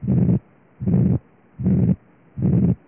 B2A desdobrada